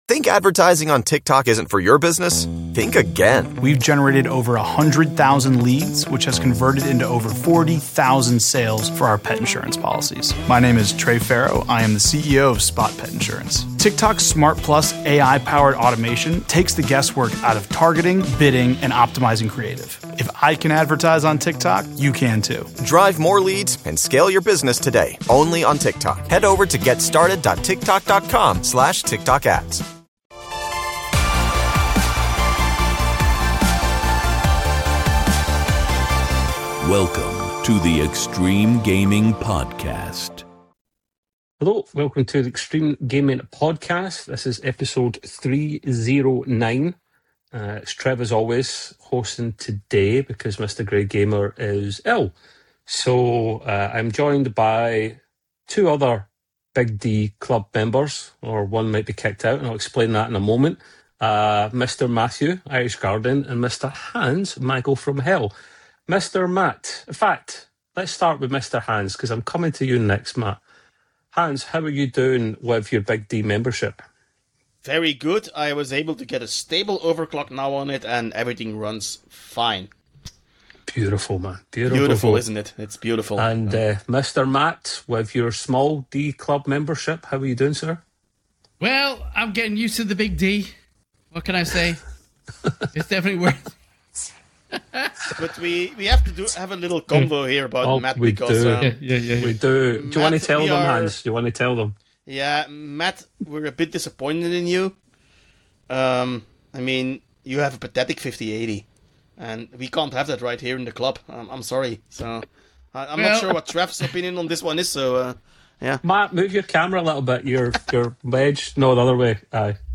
1 Alex Seropian, co-founder of Bungie (Live @ University of Chicago). 1:44:06